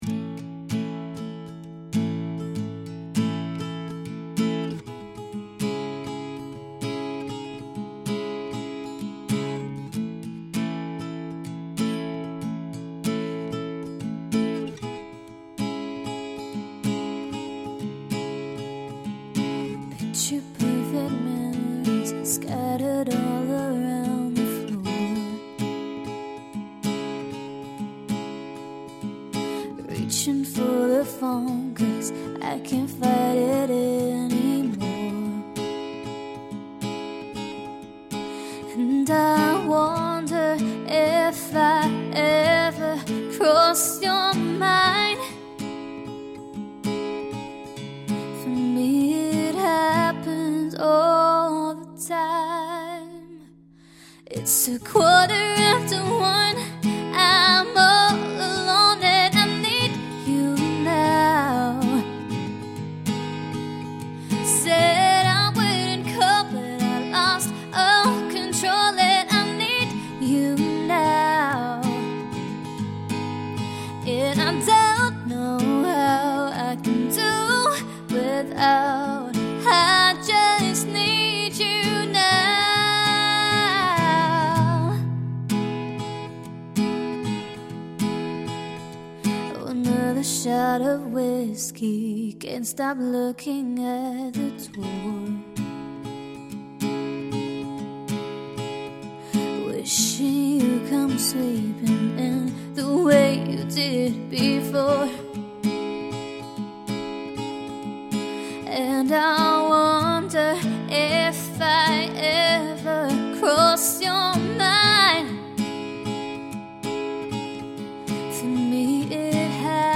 folk, country and contemporary chart music